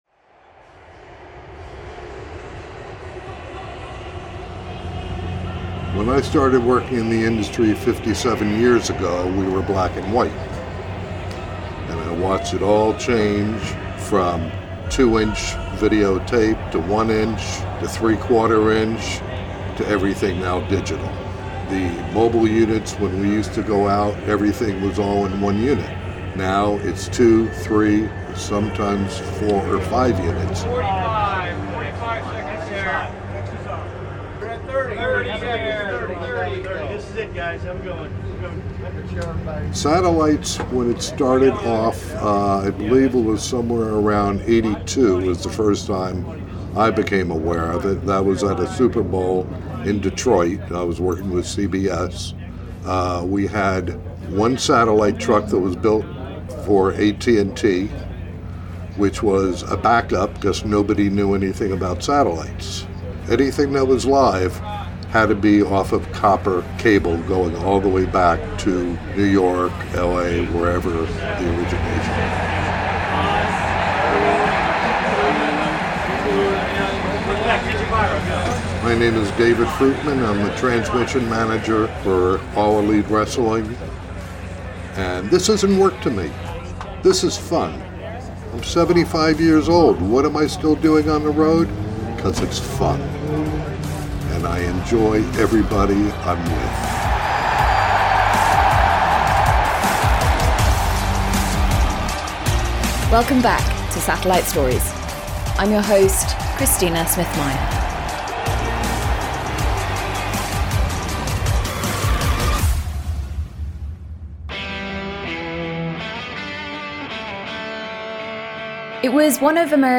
Copyright for all live match actuality belongs to All Elite Wrestling .